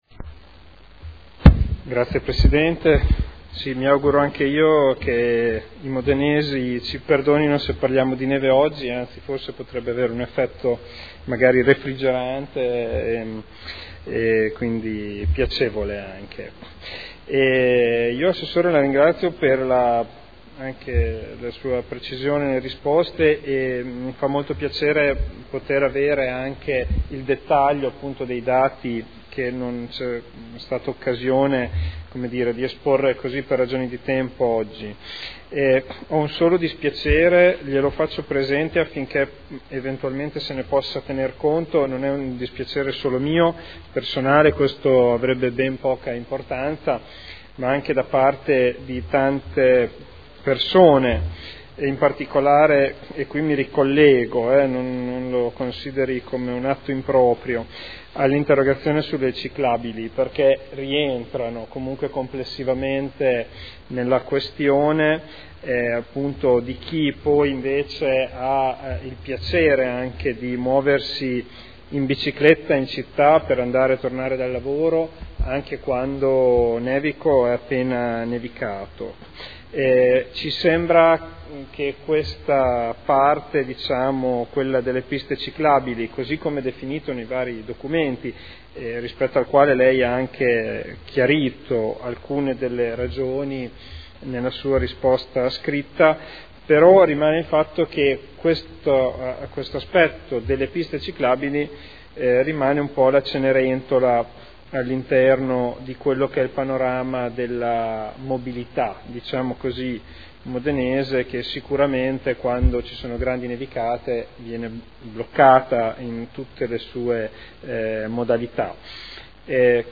Seduta del 25/06/2012. Conclude interrogazione del consigliere Ricci (Sinistra per Modena) avente per oggetto: “Costi del servizio neve”